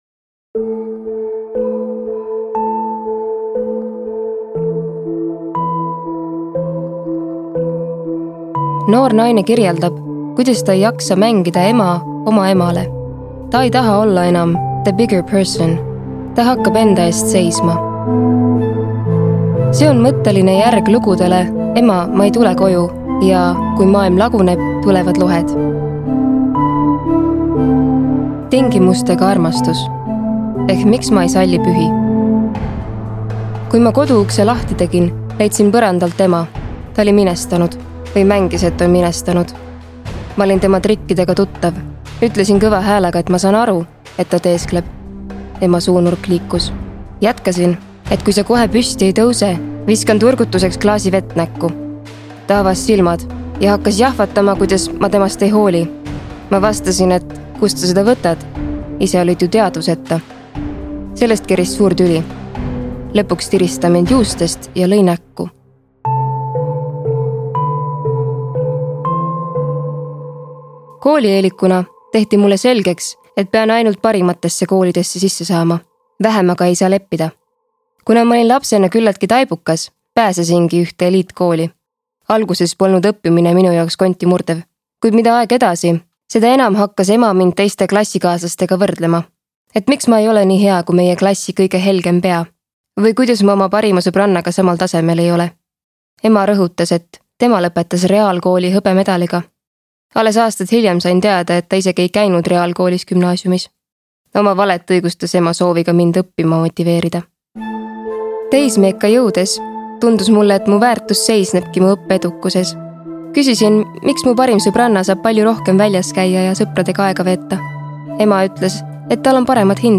tulevad lohed”.Audiolugu loeb